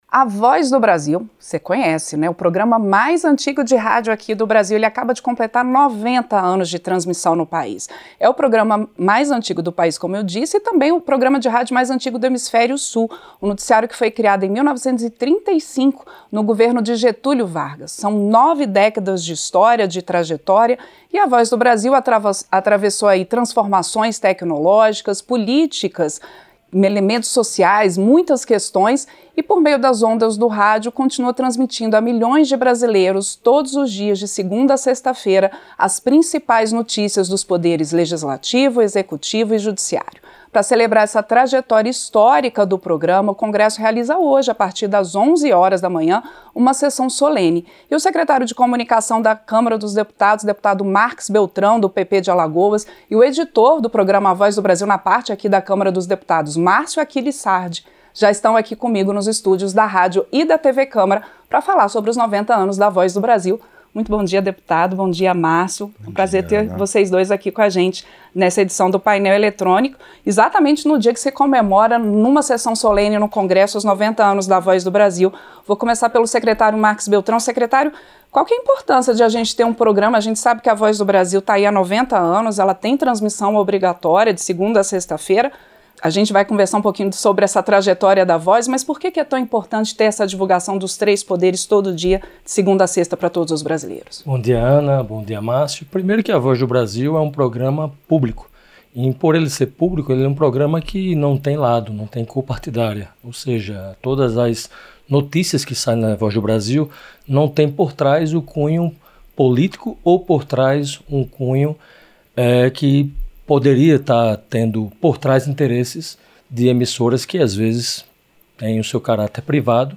Entrevista - Dep.